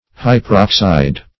Search Result for " hyperoxide" : The Collaborative International Dictionary of English v.0.48: Hyperoxide \Hy`per*ox"ide\, n. (Chem.)